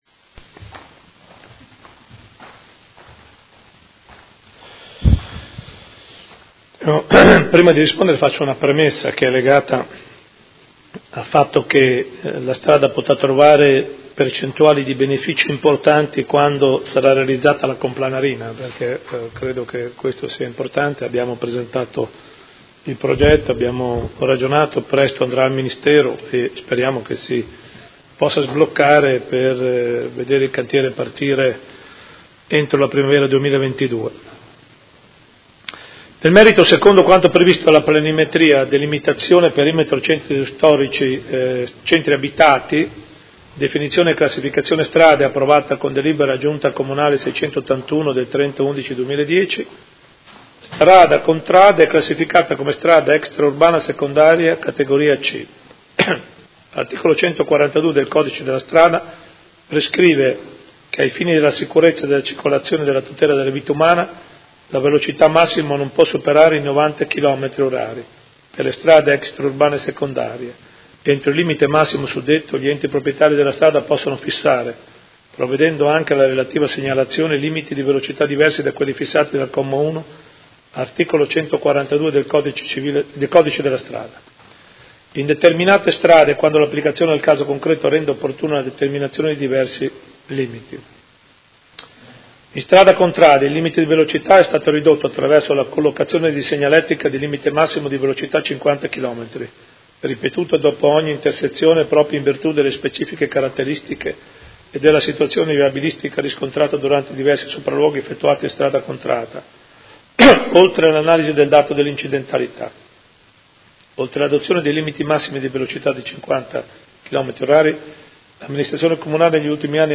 Seduta del 21/03/2019 Risponde. Interrogazione del Consigliere Carpentieri (PD) avente per oggetto: Viabilità su Strada Contrada: situazione ed interventi per migliorare la sicurezza stradale